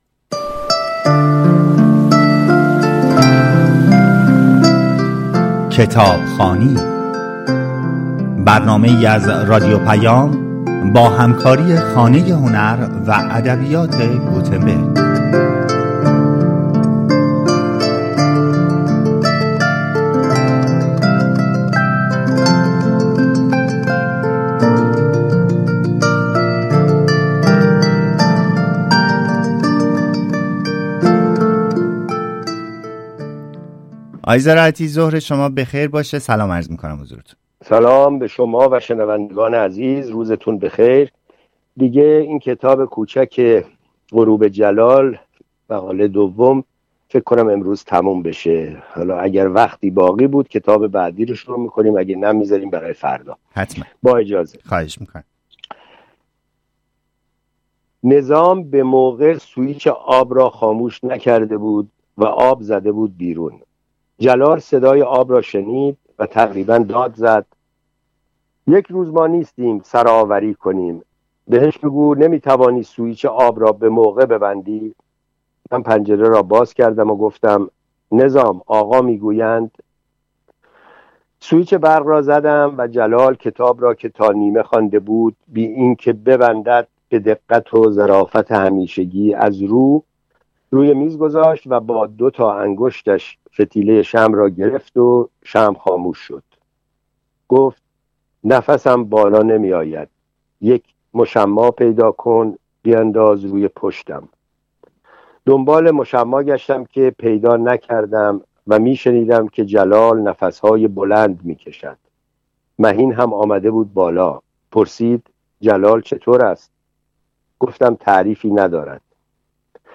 با هم این کتاب را می شنویم.